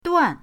duan4.mp3